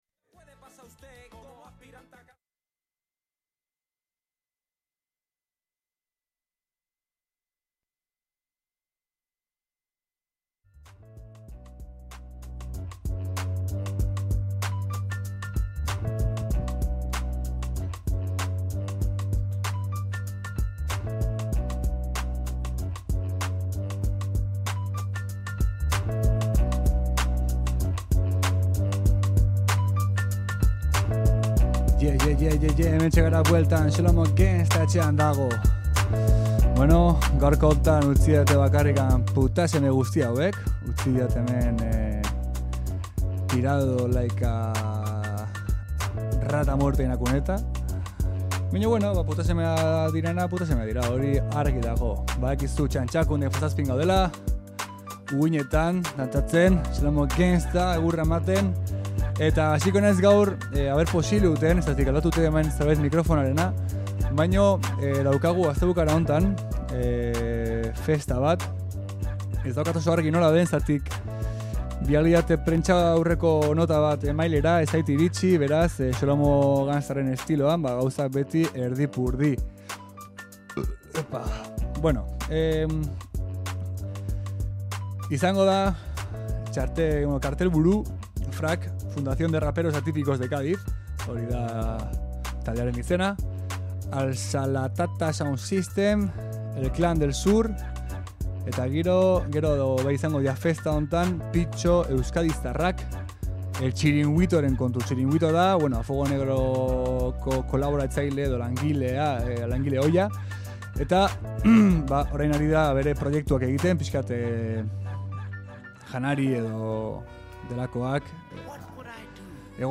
Euskal Herriko eta nazioarteko rap musika izan da entzugai Xolomo Gangsta saioan.